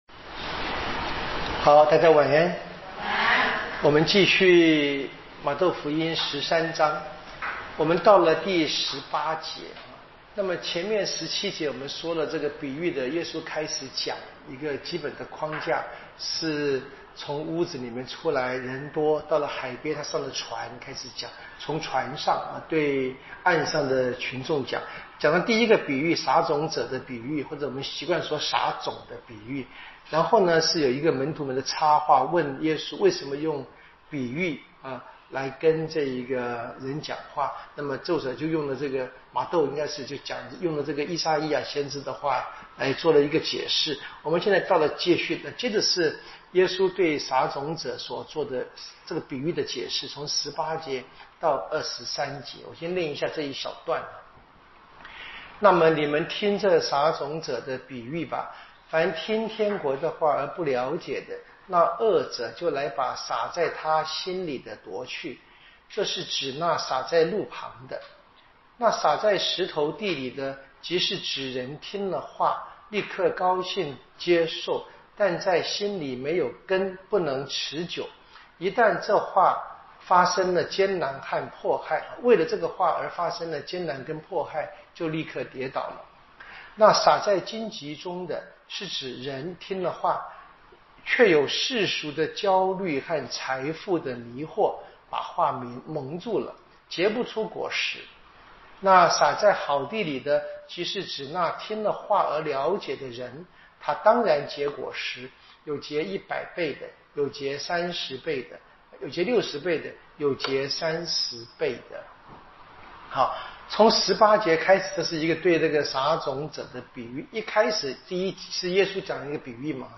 【圣经讲座】《玛窦福音》